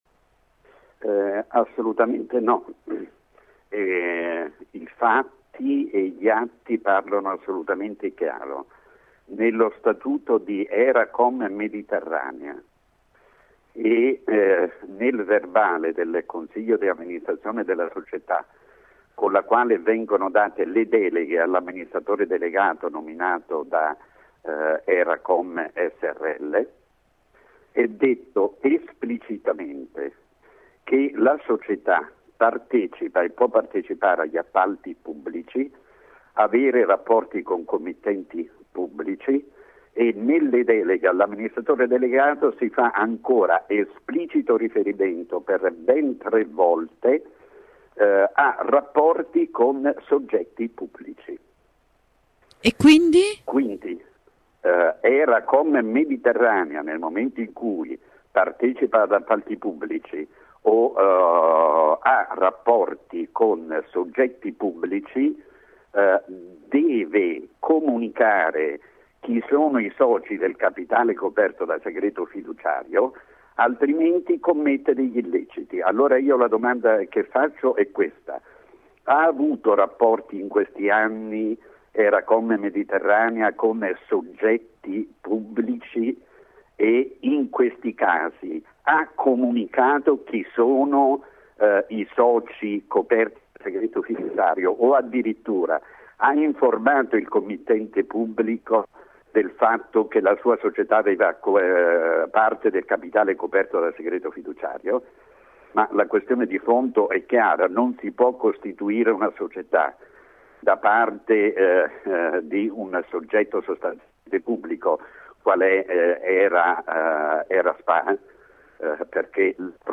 Ecco l’intervista completa